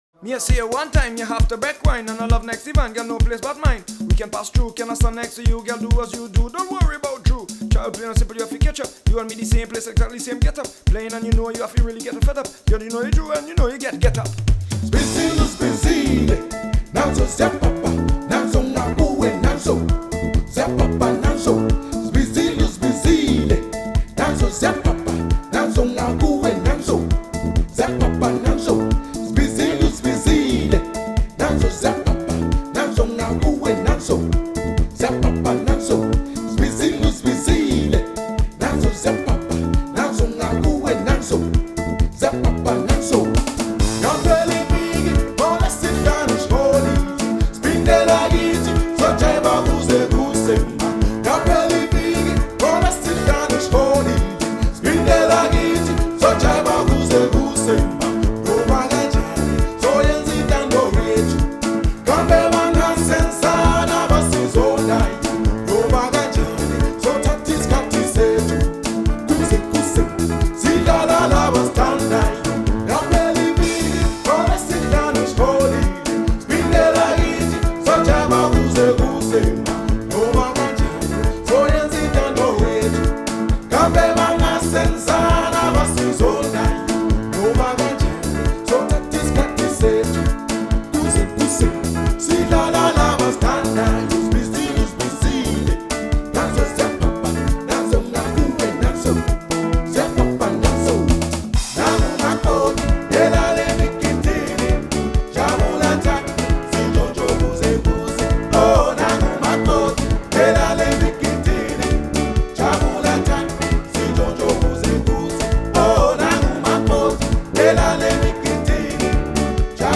up tempo and exuberant music